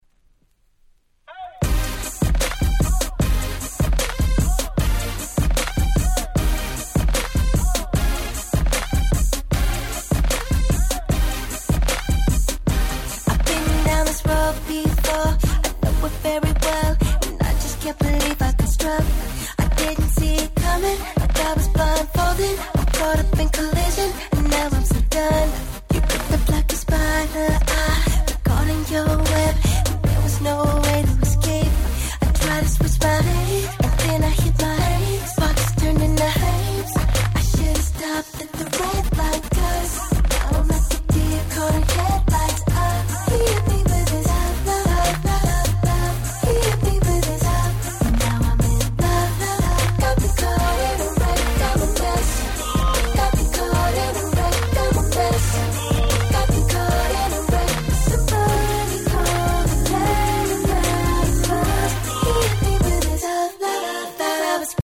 08' Smash Hit R&B !!